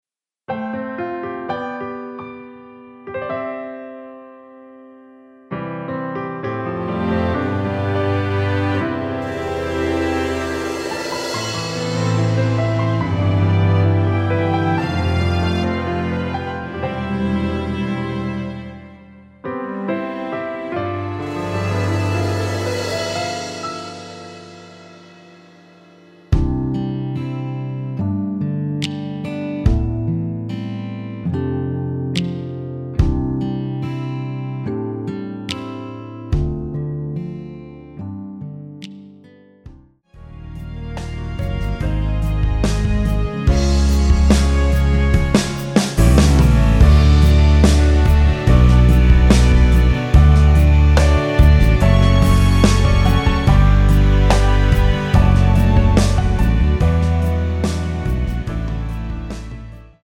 내린 MR
◈ 곡명 옆 (-1)은 반음 내림, (+1)은 반음 올림 입니다.
앞부분30초, 뒷부분30초씩 편집해서 올려 드리고 있습니다.
중간에 음이 끈어지고 다시 나오는 이유는